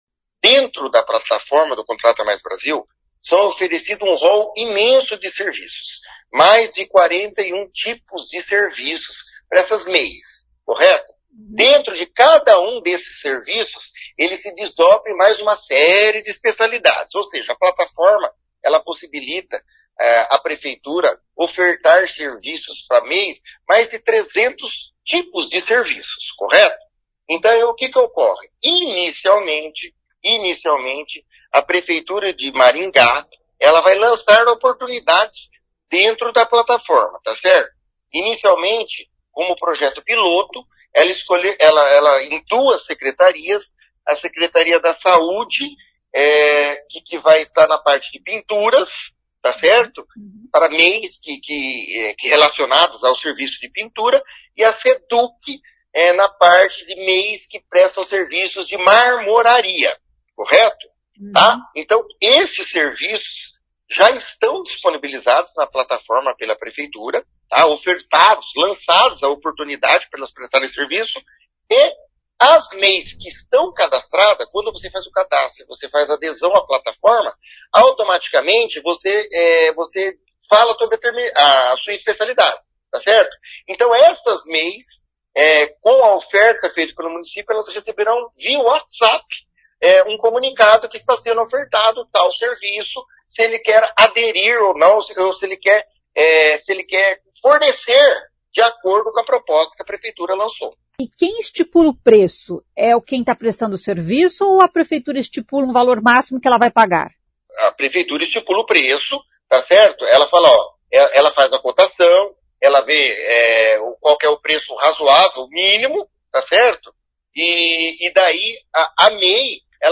O secretário explica como funciona a plataforma: